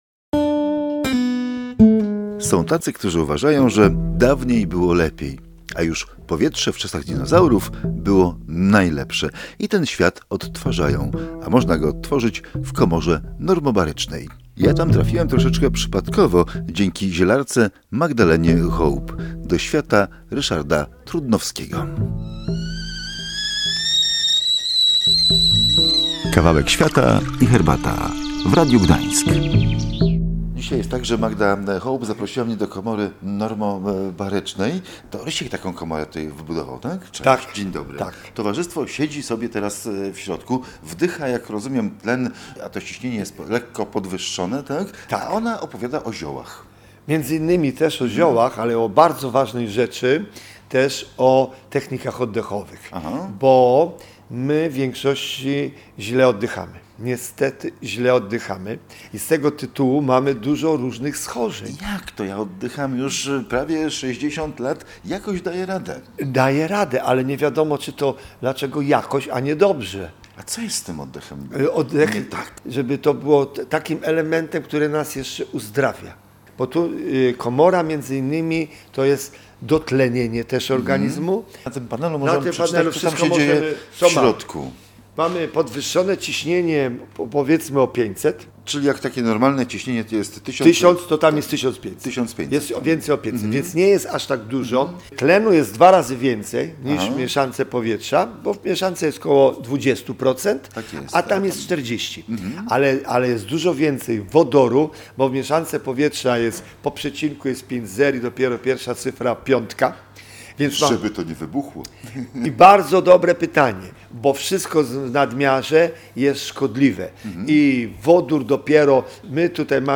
Powietrze w czasach dinozaurów było lepsze? Rozmowa w komorze normobarycznej